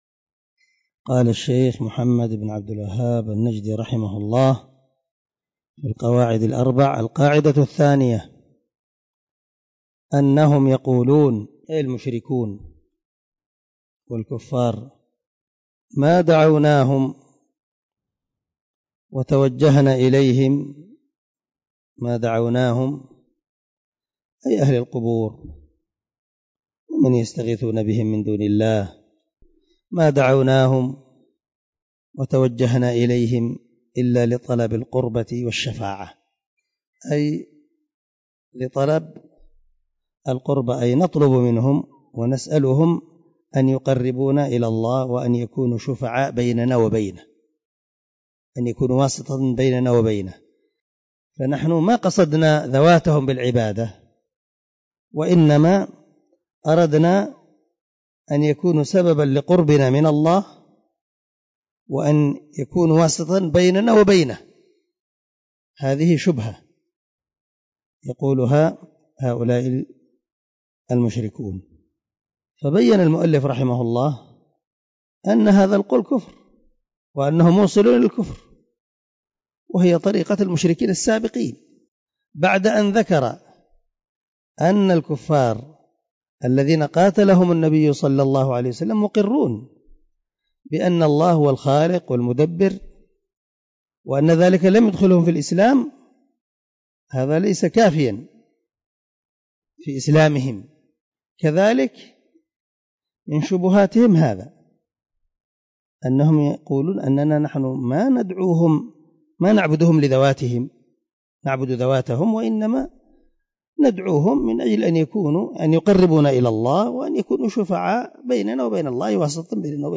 الدرس 4 القاعدة الثانية من شرح القواعد الأربع
دار الحديث- المَحاوِلة- الصبيحة.